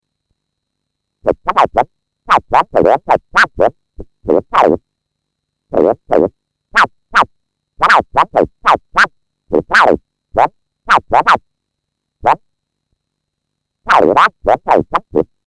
Sauf mention explicite, les enregistrements sont faits sans aucun effet.
p.82 – MS-20 : « Mars Attack » : discours d’un horrible petit extra-terrestre !
(le MS-20 fonctionne de manière totalement autonome)